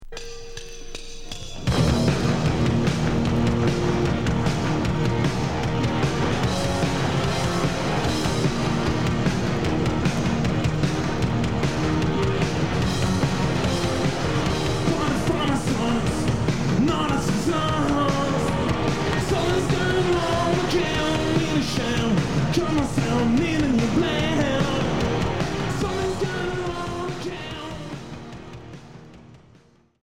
(live)
Rock garage punk